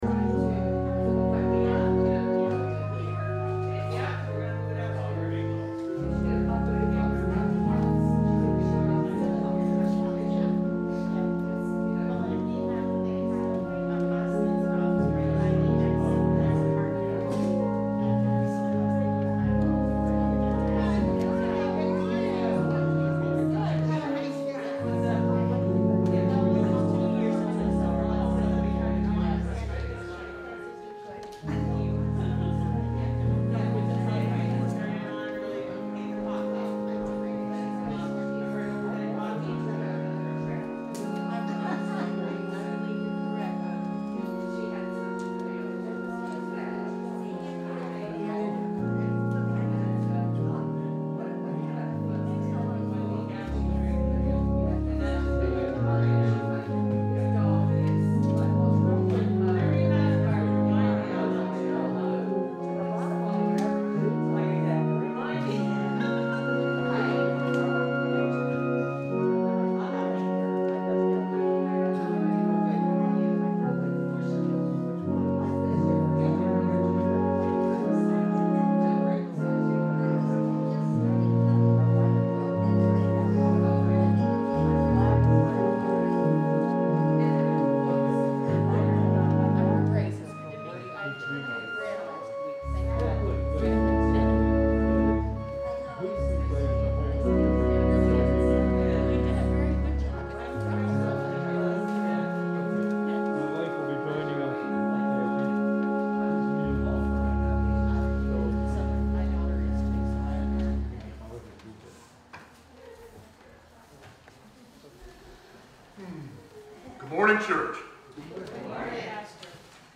Sermons Looking a gift horse in the mouth…